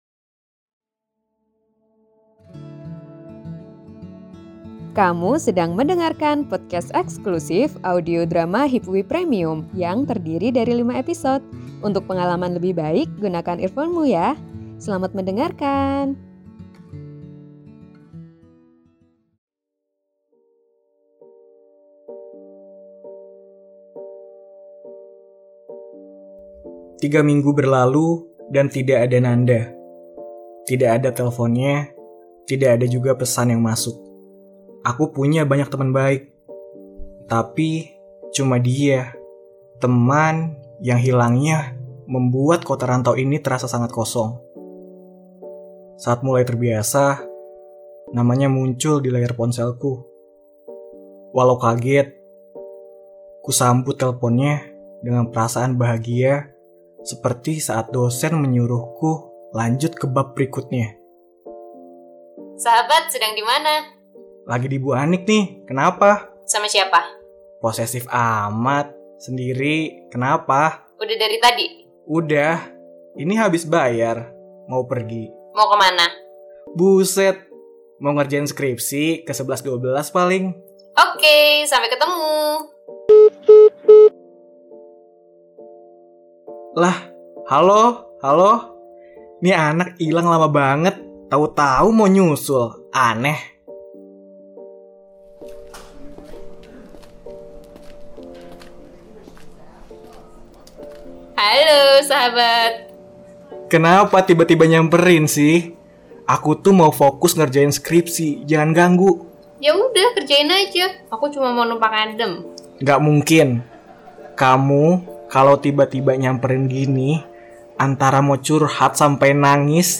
[Audio Drama] Sebatas Kembali – Episode 3